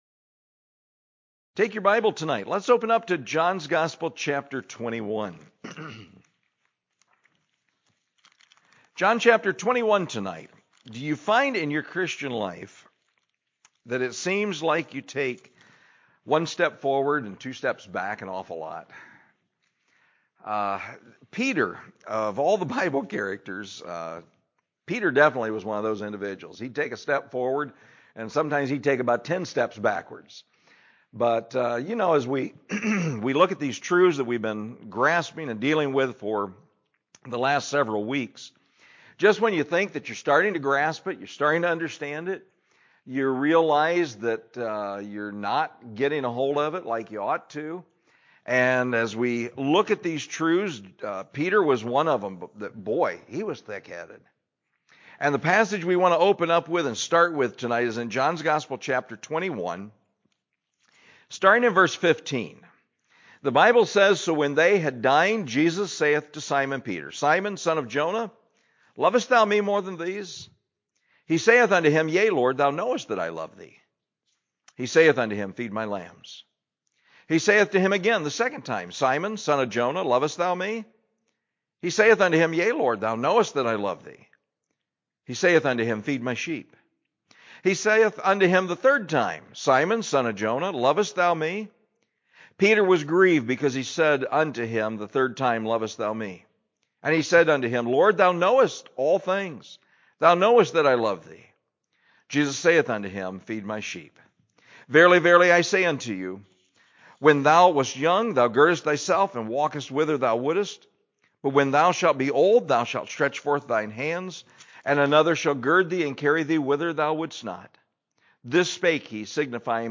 The Decree To Love God – I – MIDWEEK – 6/23/21 – First Baptist Church Bryan